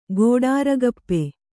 ♪ gōḍāragappe